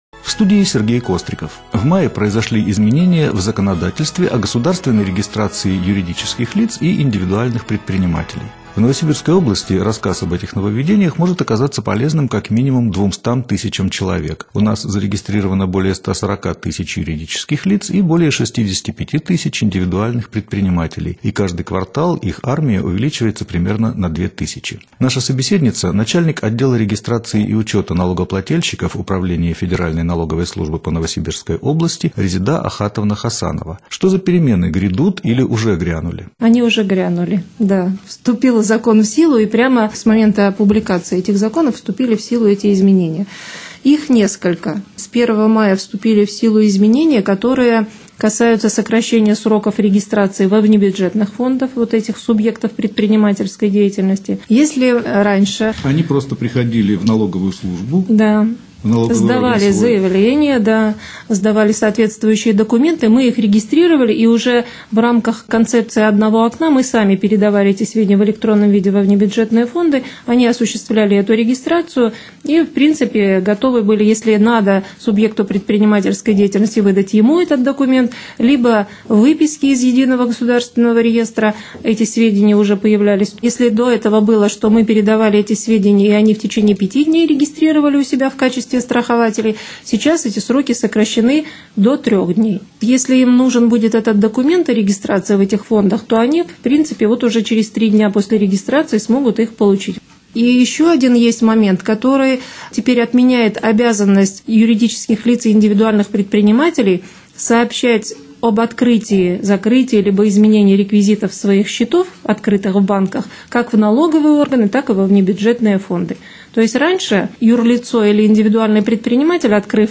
Сюжет прозвучит в программе «Микрофорум» в эфире радио «ГТРК Новосибирск» 22 мая в 7:10 и в 18:10.